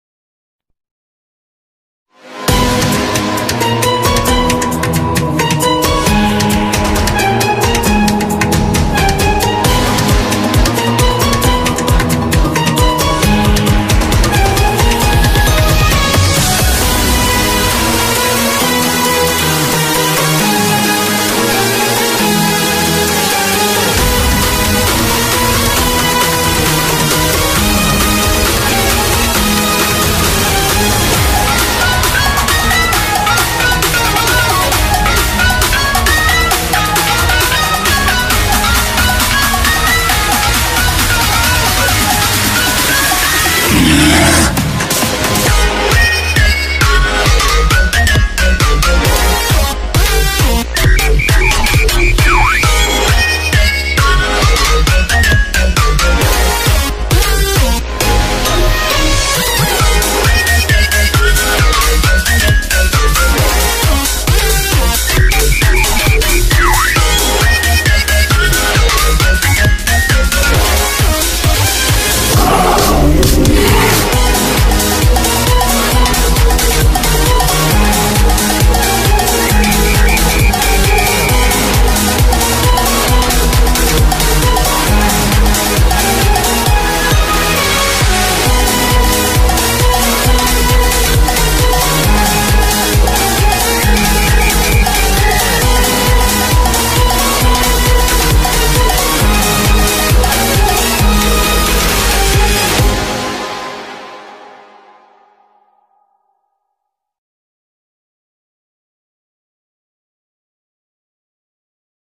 BPM134
Audio QualityLine Out